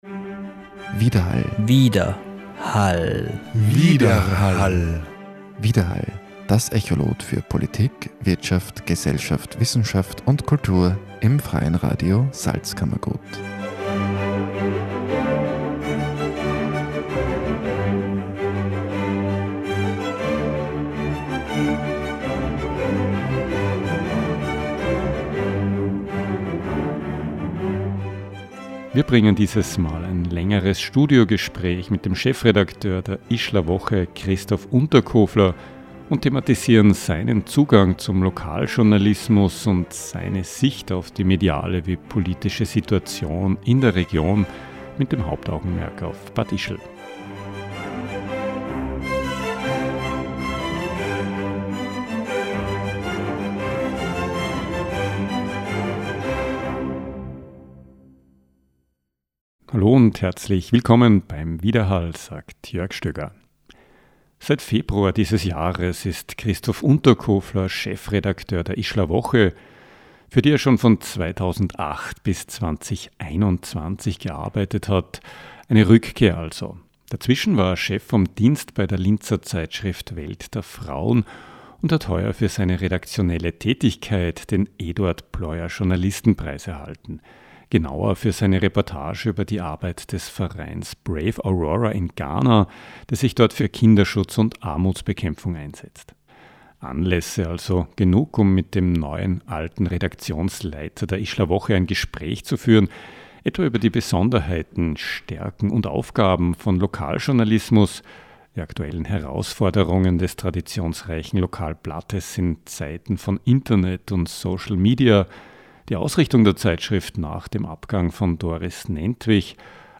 Im Studiogespräch geht er u.a. auf die Stärken und Aufgaben von Lokaljournalismus, die aktuellen Herausforderungen für die Ischler Woche in Zeiten von Internet und social media, sein Verständnis von Journalismus und allgemein auf die politische Situation in der Region und im Speziellen auf das politische Klima in Bad Ischl ein.